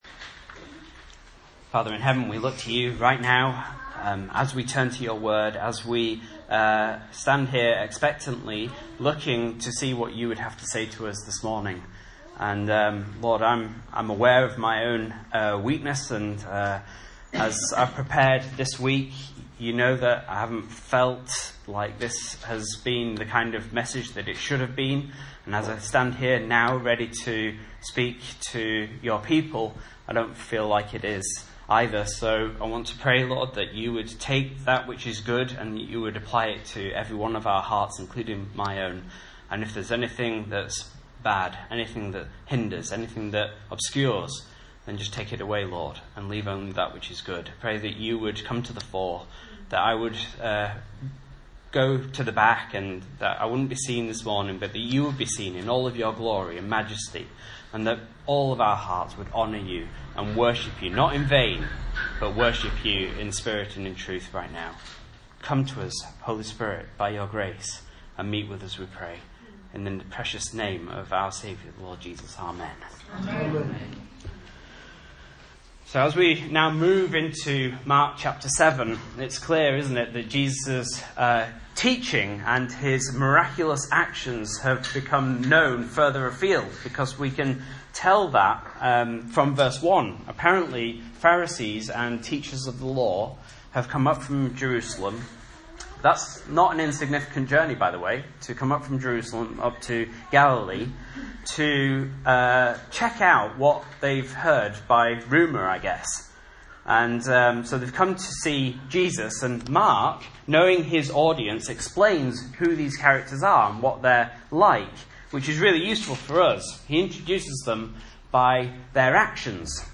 Message Scripture: Mark 7:1-23 | Listen